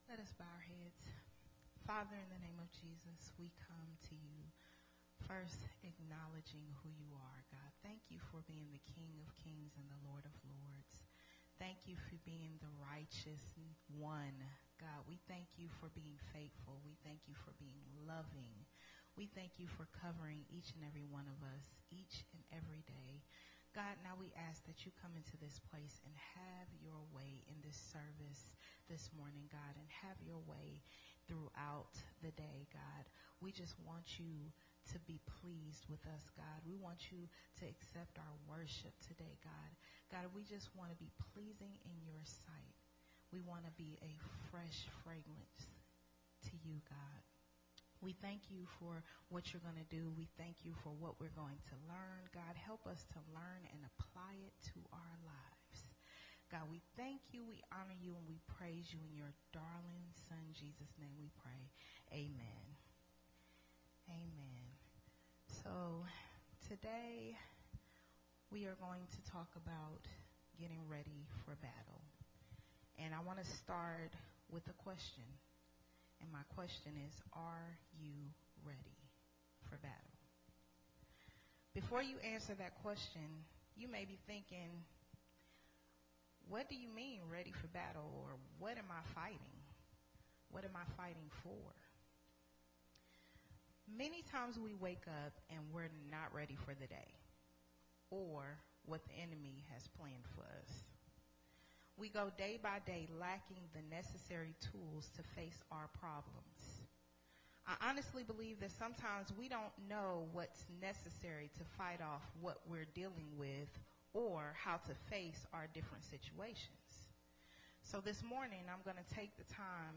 Sunday Morning Refreshing